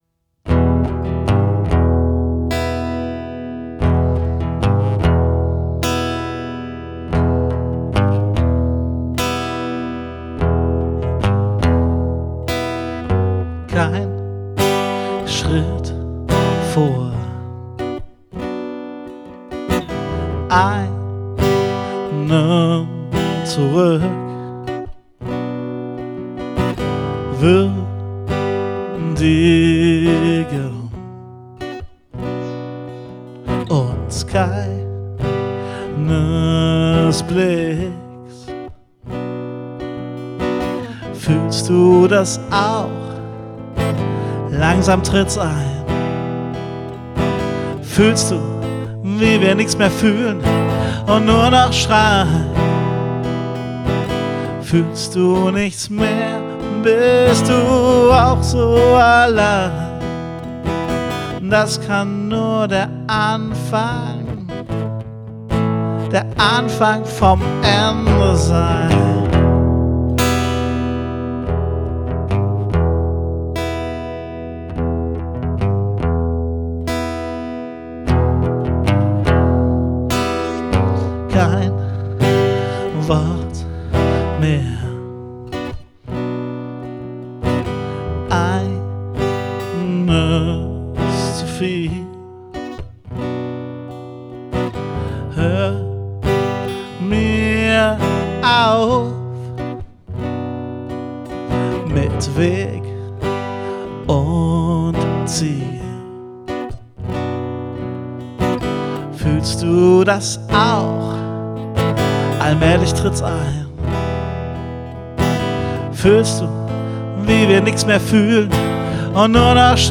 Aufgenommen und gemischt am 29. Juli 2017